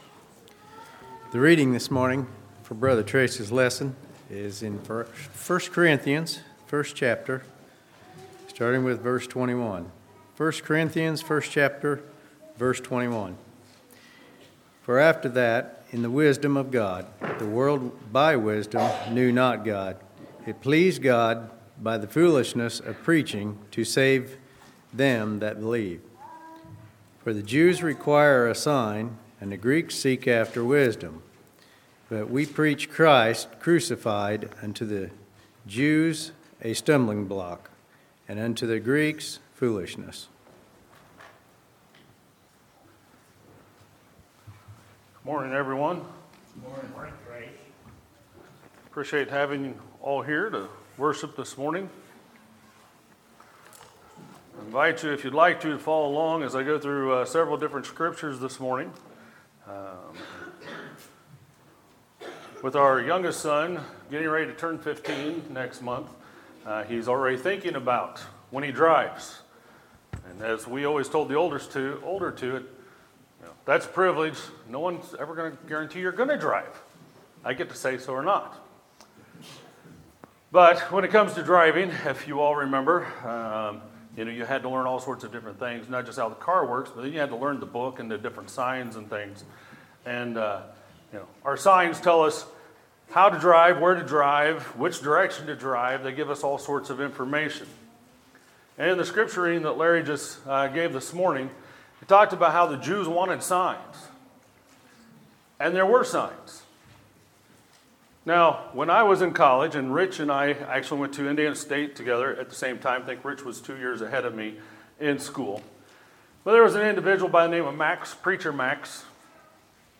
Sermons, March 18, 2018